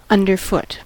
underfoot: Wikimedia Commons US English Pronunciations
En-us-underfoot.WAV